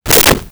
Whip 05
Whip 05.wav